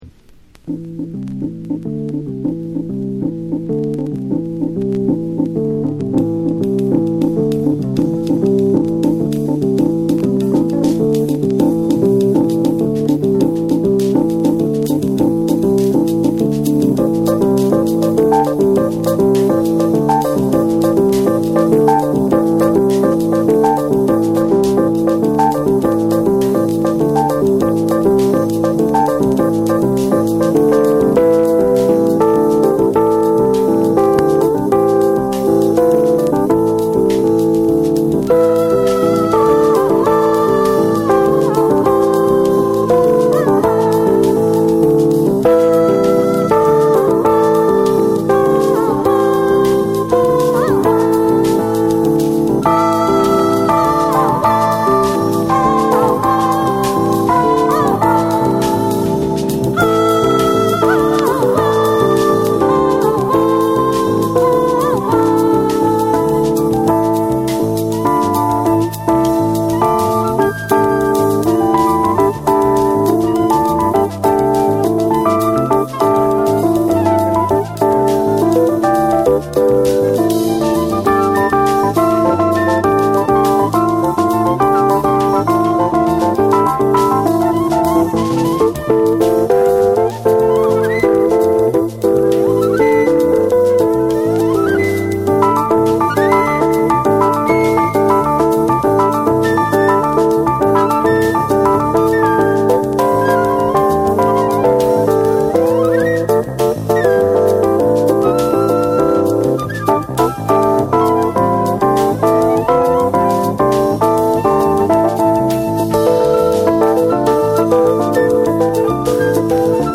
SOUL & FUNK & JAZZ & etc